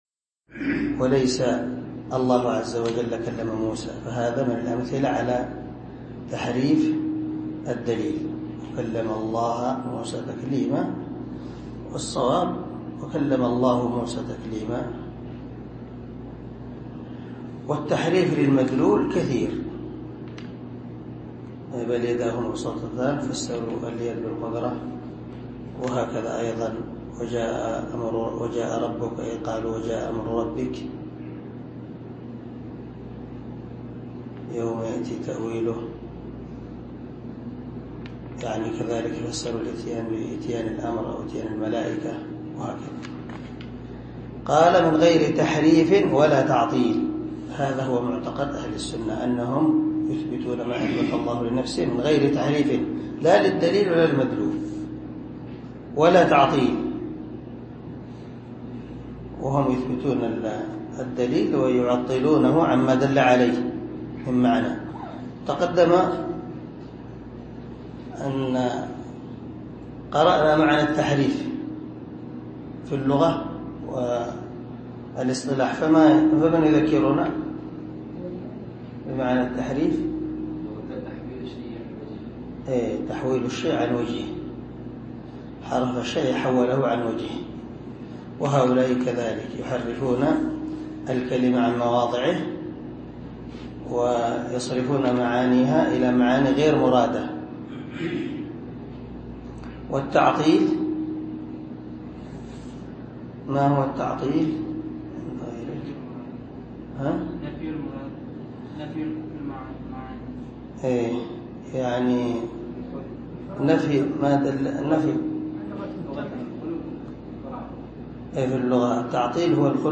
عنوان الدرس: الدرس العاشر
دار الحديث- المَحاوِلة- الصبيحة